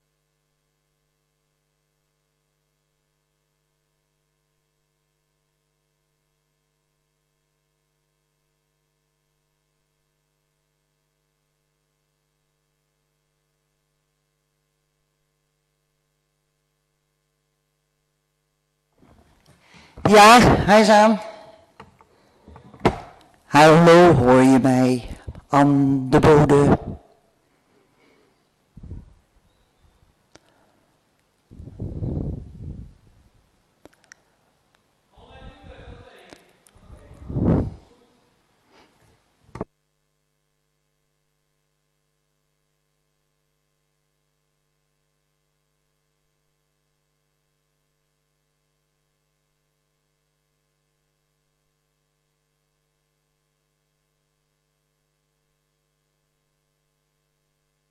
Locatie: Breukelenzaal- gemeentehuis Boom & Bosch, Markt 13, 3621 AB Breukelen.